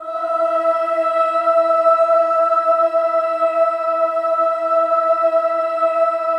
VOWEL MV11-L.wav